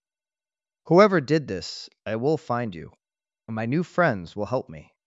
novafarma/assets/audio 🔴/voiceover/prologue/prologue_15.wav